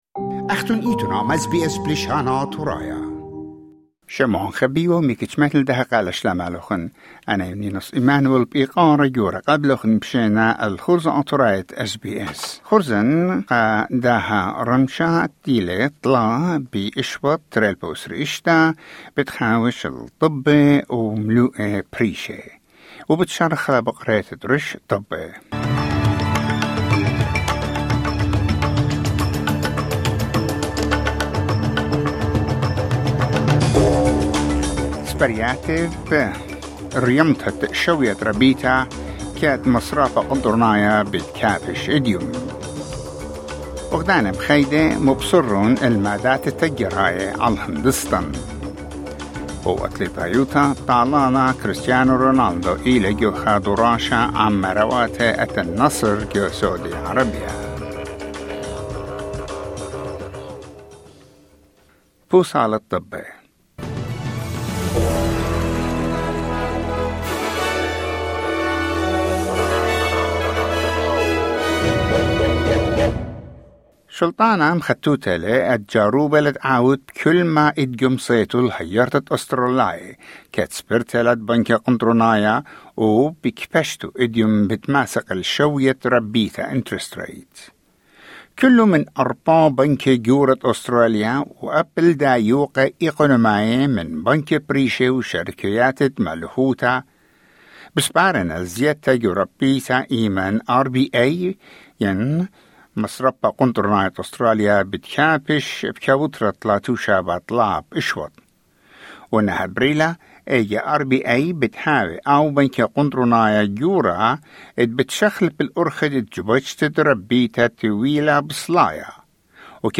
New bulletin 3 February 2026